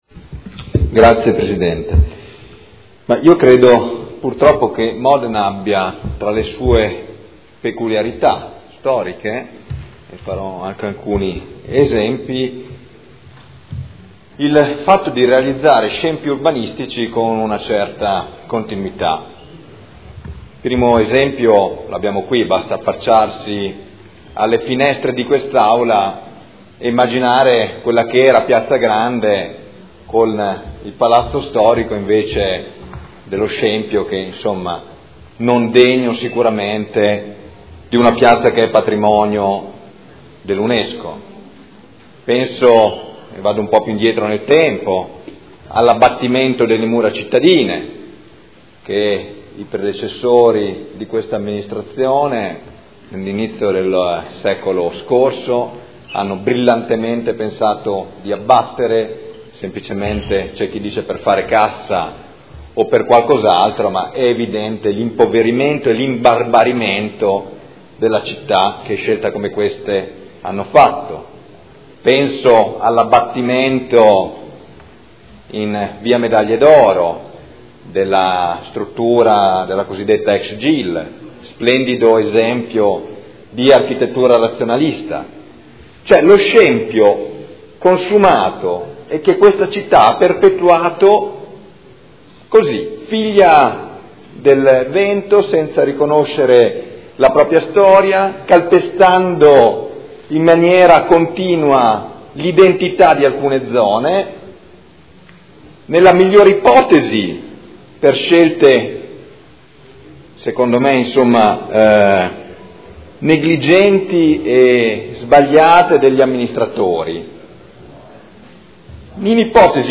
Michele Barcaiuolo — Sito Audio Consiglio Comunale